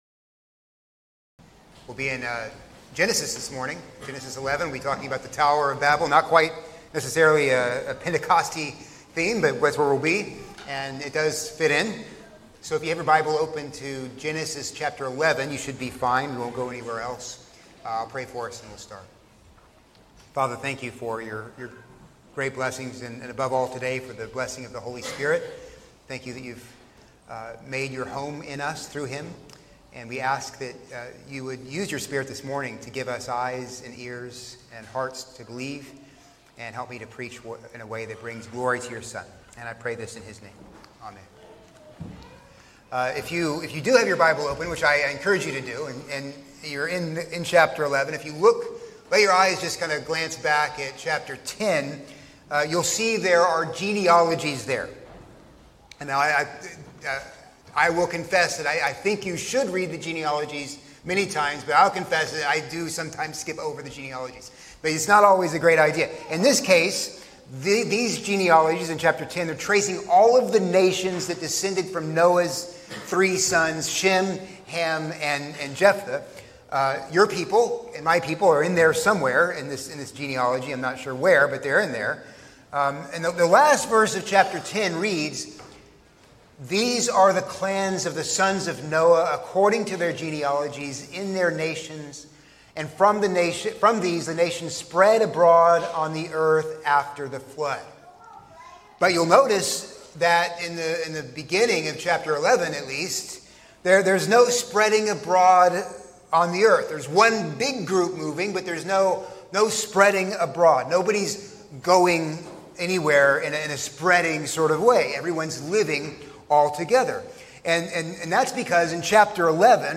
A sermon on Genesis 11:1-9